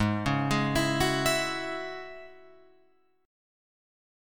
G#7sus4#5 chord